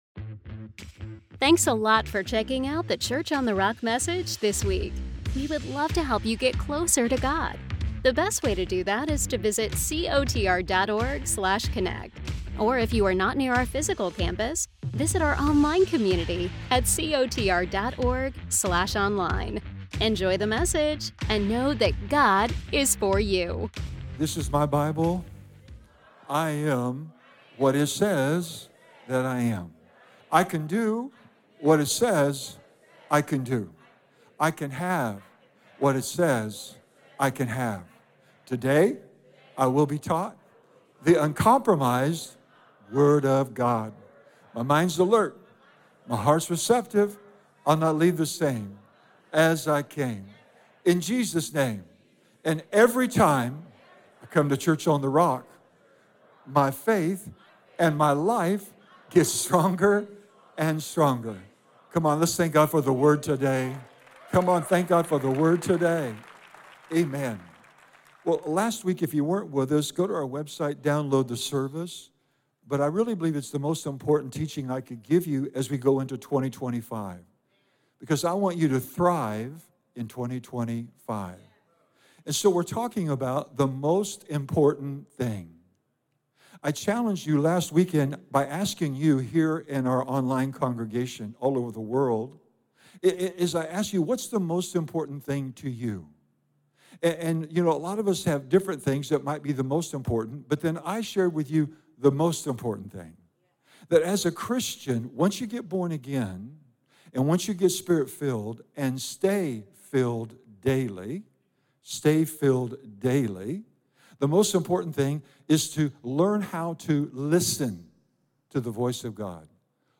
In this powerful message